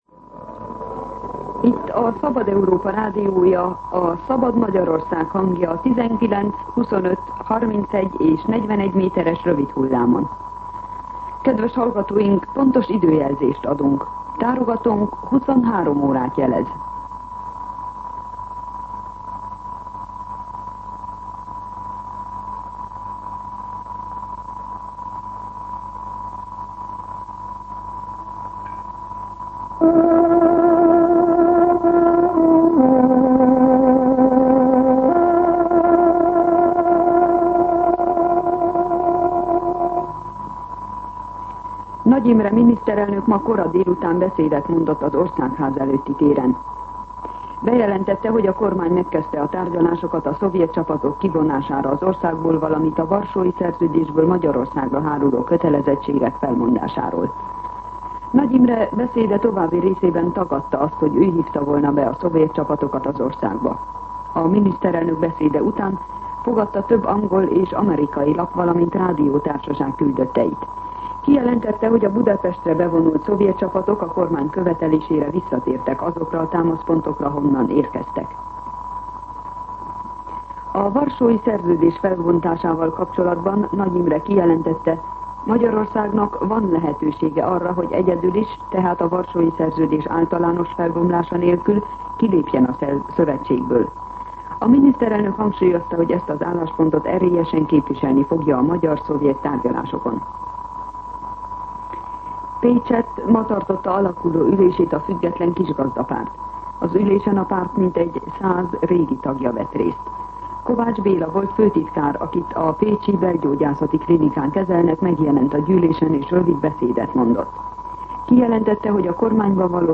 23:00 óra. Hírszolgálat